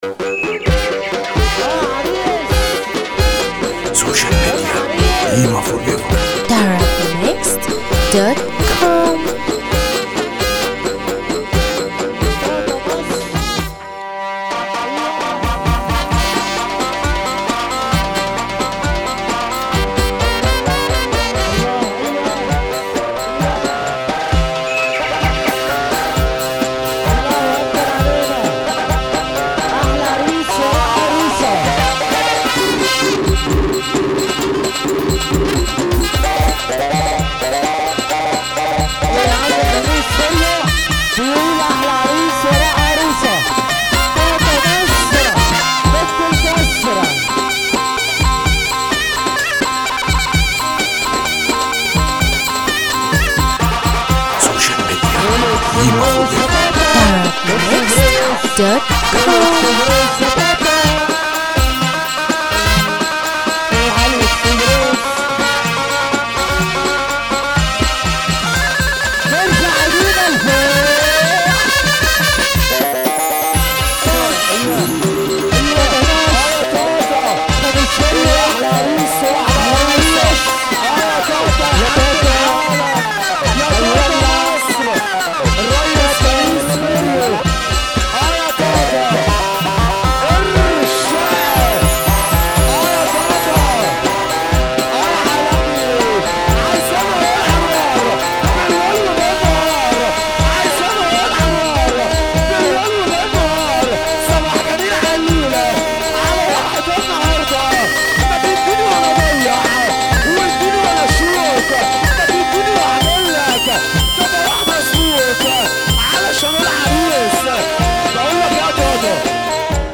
مزمار